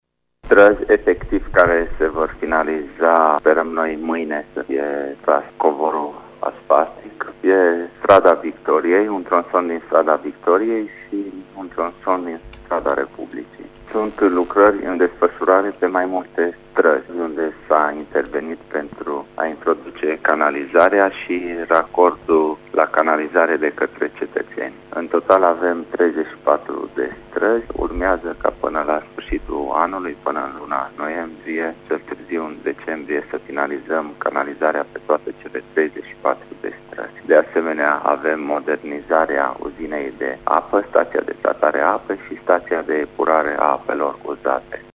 Primarul din Târnăveni, Sorin Megheşan, a precizat pentru RTM că mâine vor fi finalizate lucrările pe două străzi, iar până la finalul anului toate lucrările investiţiile trebuie terminate: